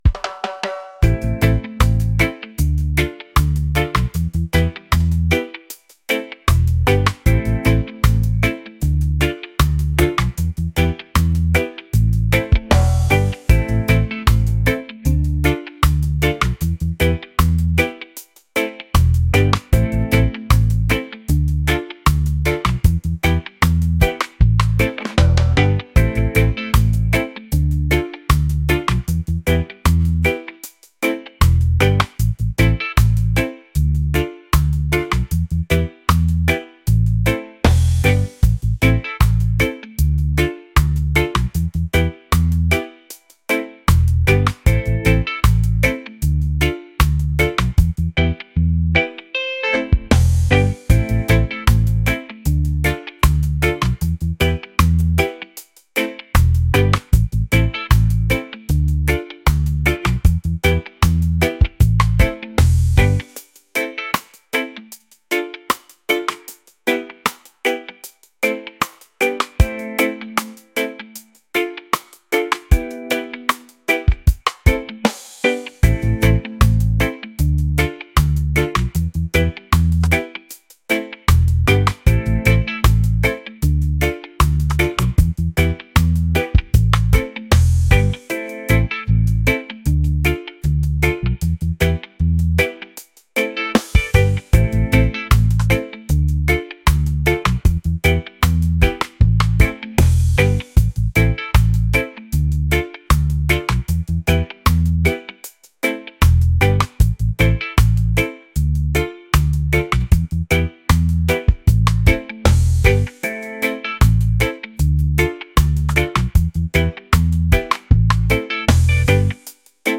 laid-back | reggae | relaxed